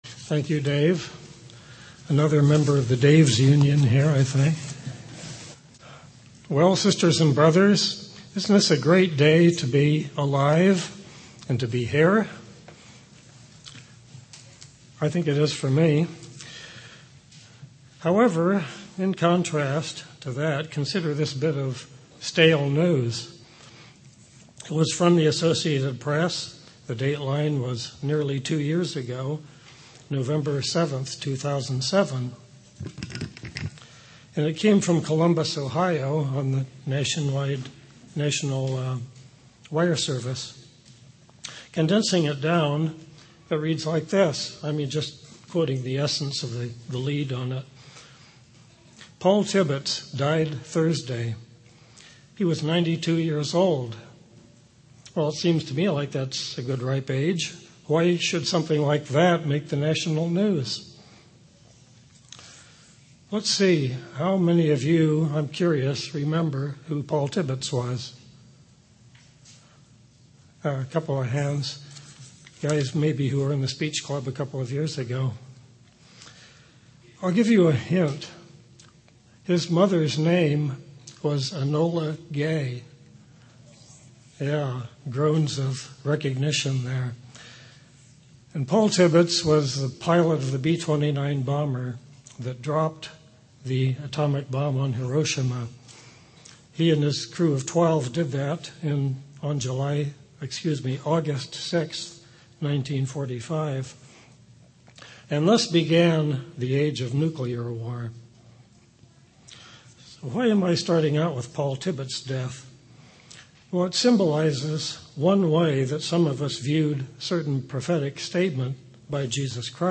Given in Orange County, CA
UCG Sermon Studying the bible?